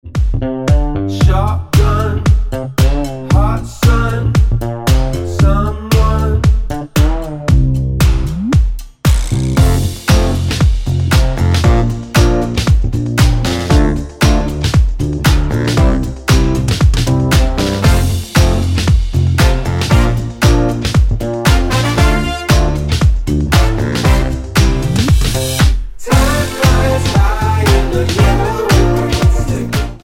Tonart:F mit Chor